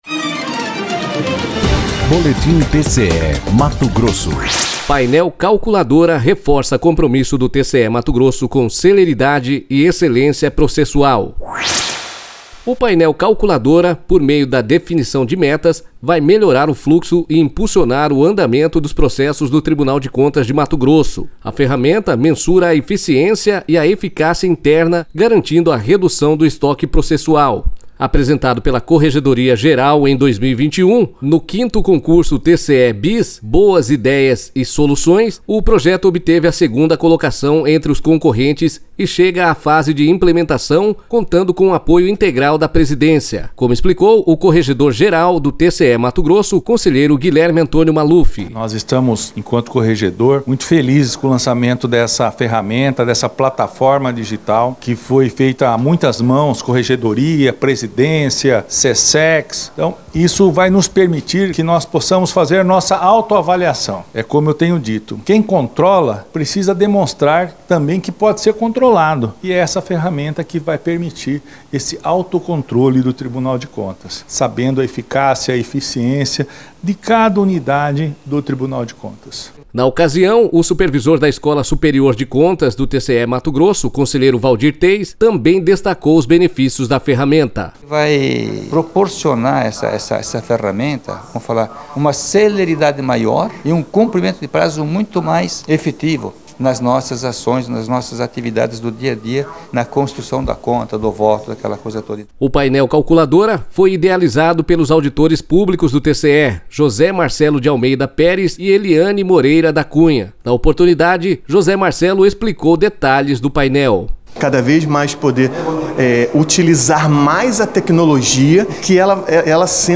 Sonora: Guilherme Antonio Maluf – conselheiro corregedor-geral do TCE-MT
Sonora: Waldir Teis – conselheiro supervisor da Escola Superior de Contas do TCE-MT